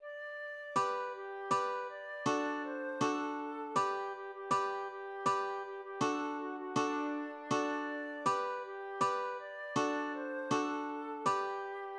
\version "2.18.2" \paper { print-page-number = ##f } \header{ dedication = "🏰031" title = "Wer ist der Graf?" subtitle = "Schandlied aus der Zeit der Natterndorner Fehde" subsubtitle = "
LilyPond 🏰" } myMusic = { << \chords { \germanChords \set chordChanges=##t \set Staff.midiInstrument="acoustic guitar (nylon)" s4
g4 } \relative c' { \time 2/4 \tempo 4=80 \partial 4 \key g \major \set Staff.midiInstrument="flute" d'4^\fermata